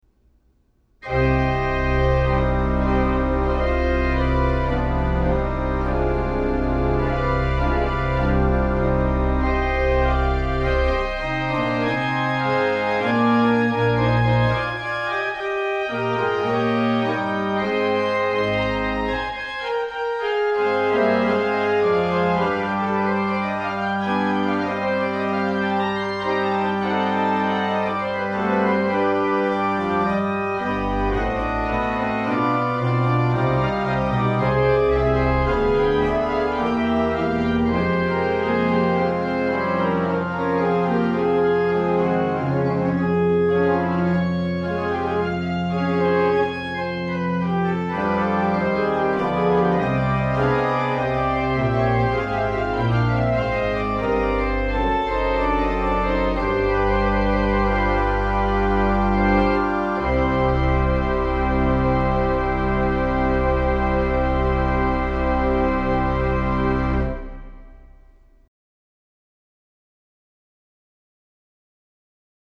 is intended to demonstrate the stops of Opus 27.